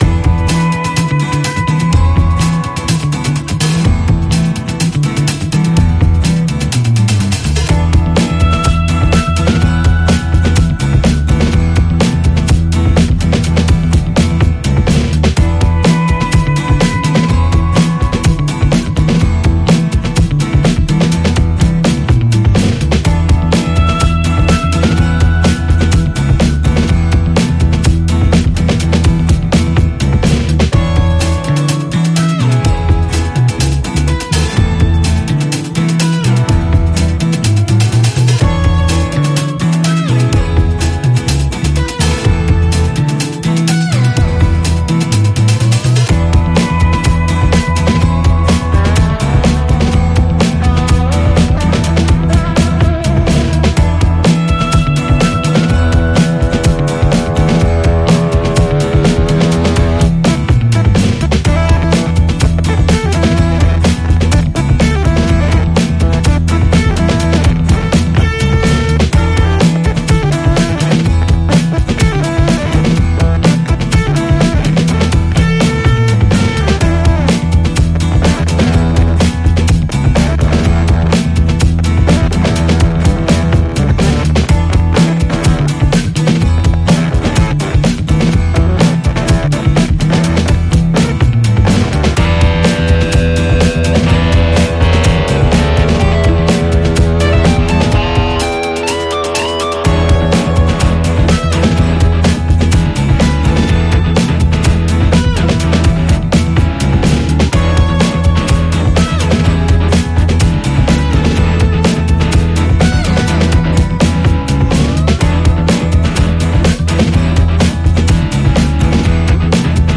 That lead fuzz solo I cannot get to sit nicely without disappearing simultaneously.
Also, did I crush it a bit too hard?